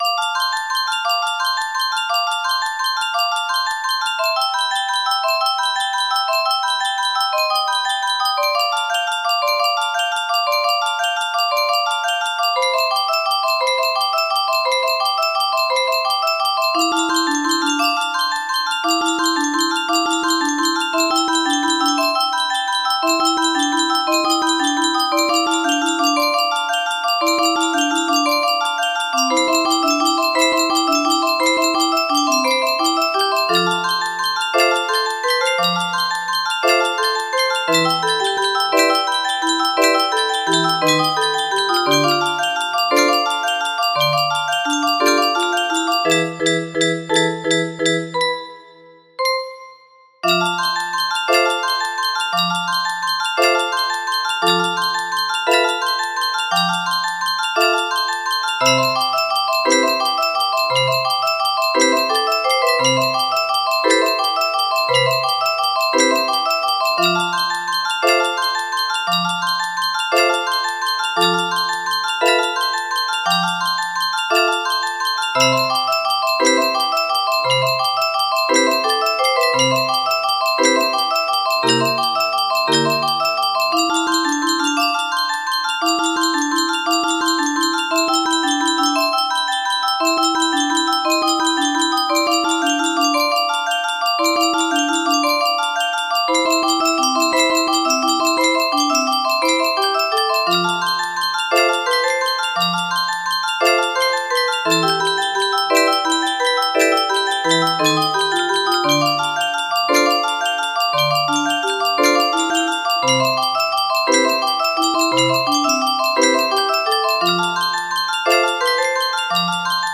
Full range 60
in a music box!